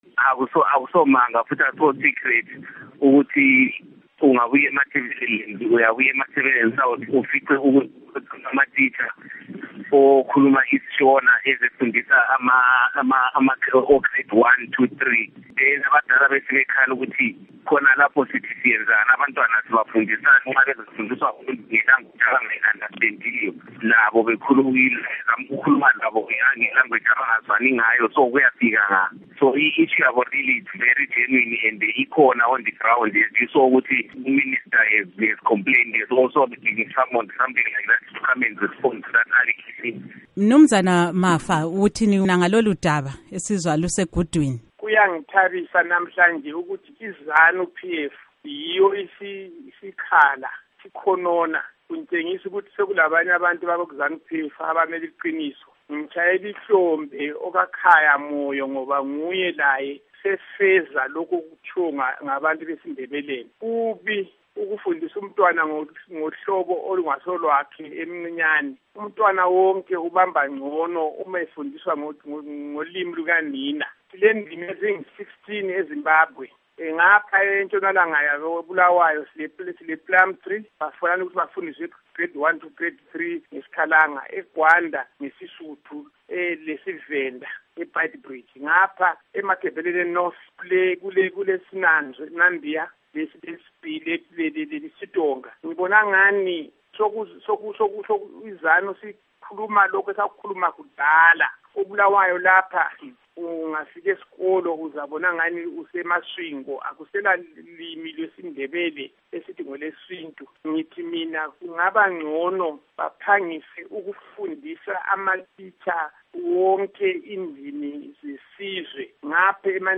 Ingxoxo Phakathi KukaMnu.